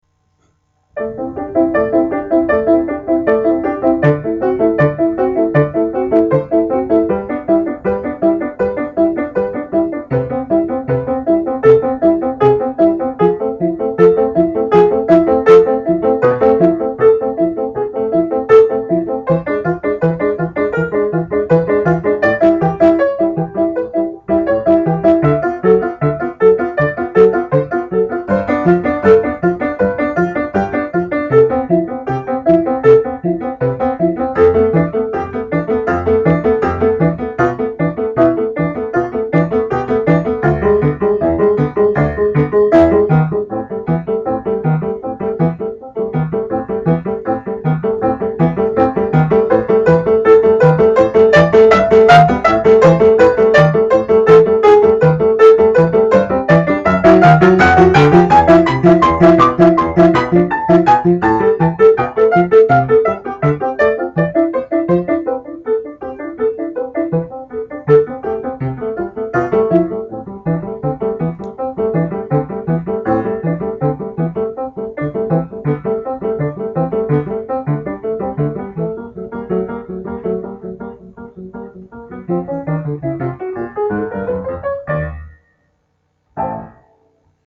Keyboard Etude